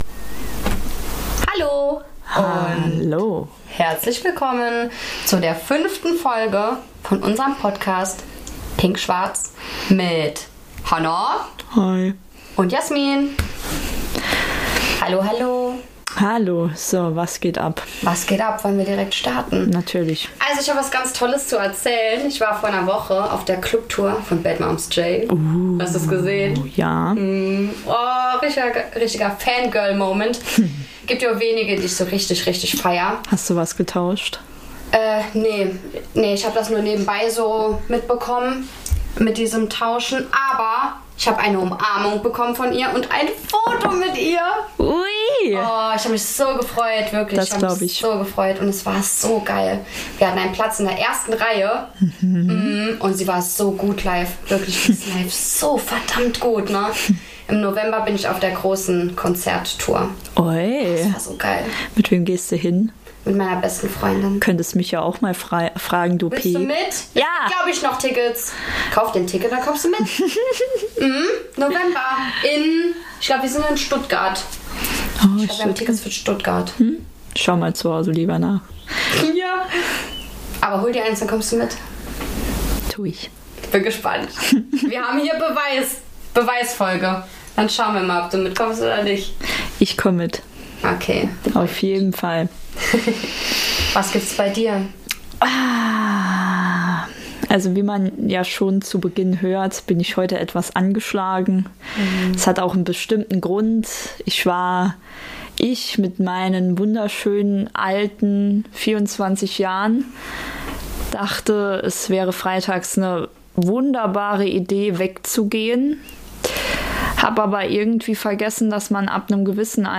In dieser Folge geht es zurück an den eigentlichen Ursprung des Podcastes. Die Podcasterinnen sprechen über Cluberlebnisse, Datingfails und einhergehende Horrorgeschichten.